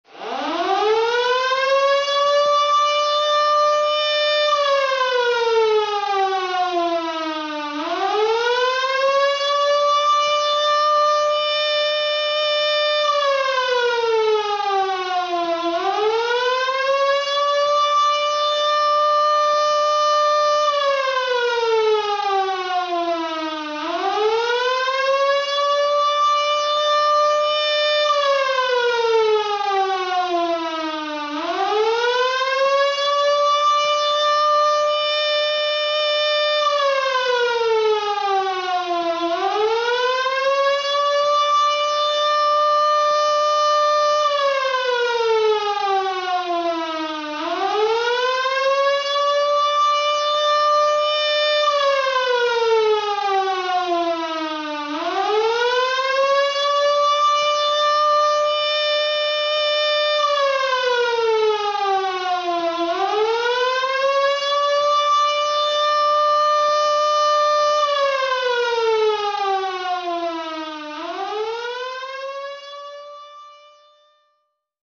На этой странице собраны звуки военной тревоги: сирены, сигналы оповещения и другие тревожные аудиофайлы.
Звук сирены при угрозе